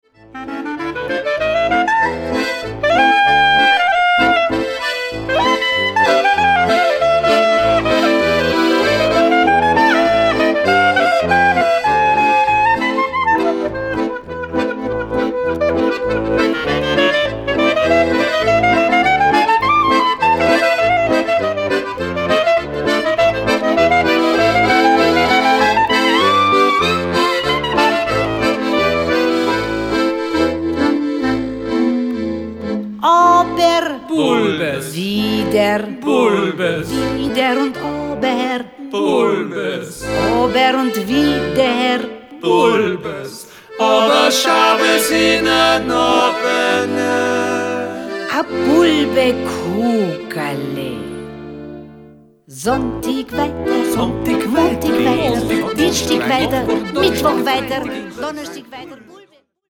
altem, mündlich überliefertem Volksliedgut aus Osteuropa